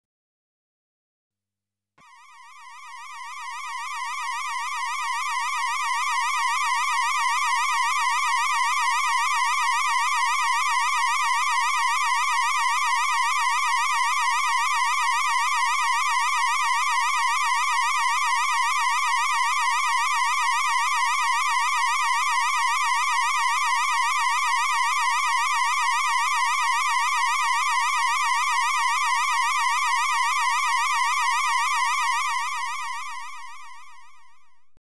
Электронные звуки: сирена № 3